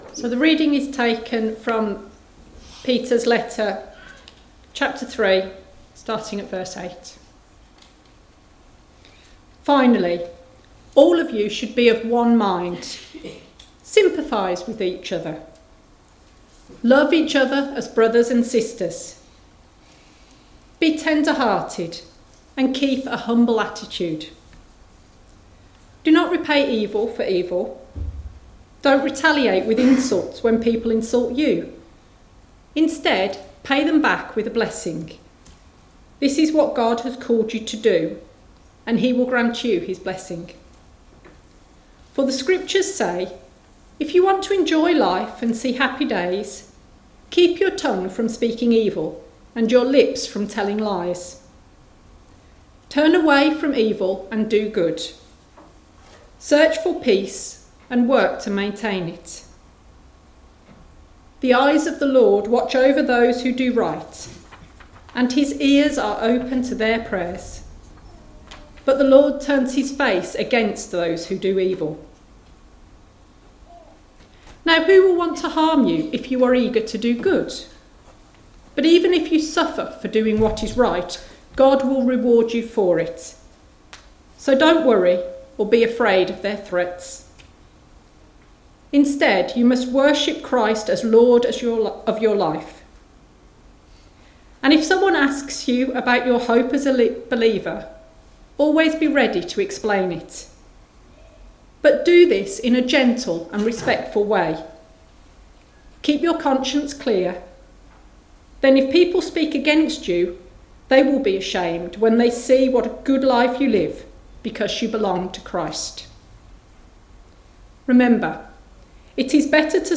you can listen to the reading and sermon by tapping the link below